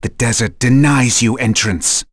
Zafir-Vox_Skill2.wav